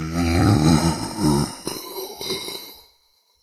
burer_idle_1.ogg